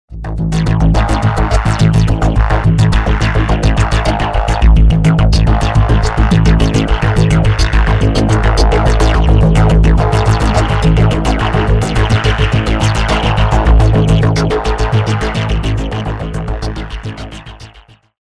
Demo Beat Resampler Mix